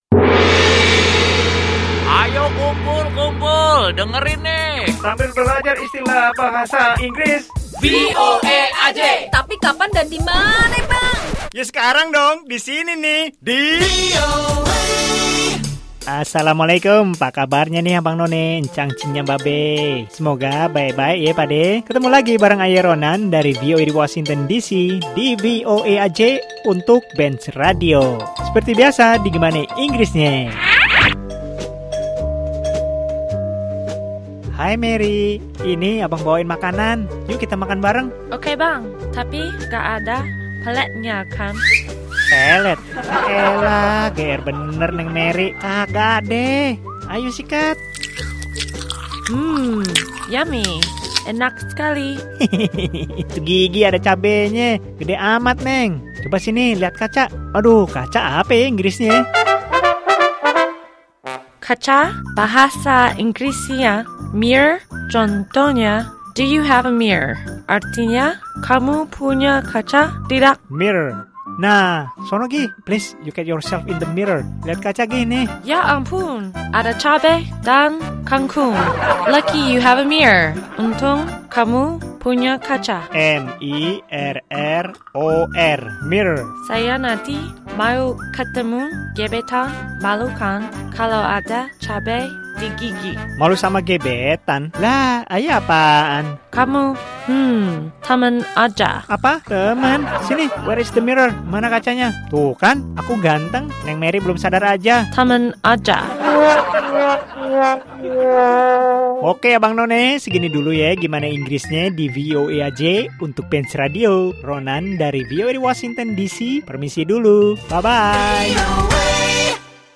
Kali ini ada percakapan